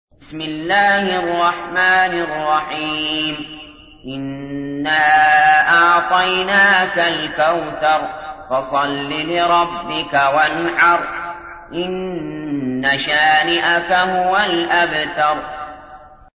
تحميل : 108. سورة الكوثر / القارئ علي جابر / القرآن الكريم / موقع يا حسين